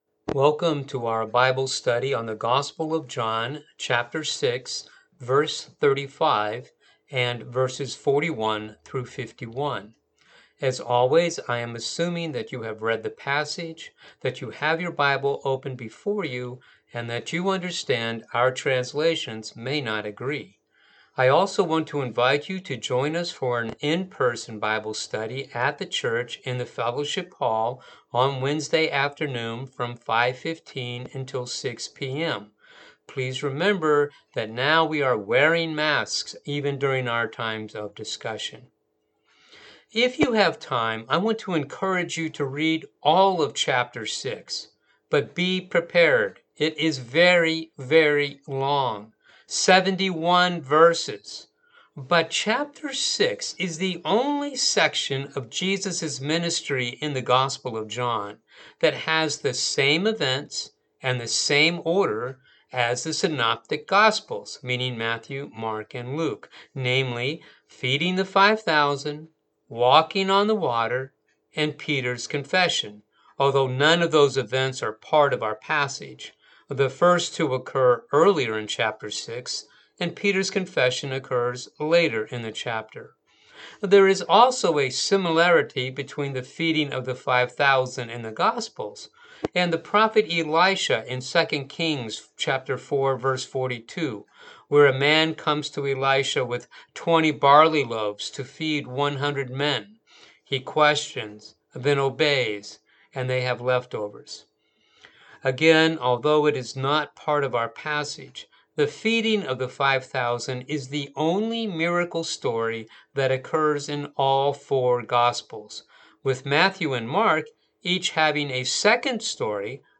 Bible Study For The August 8th Service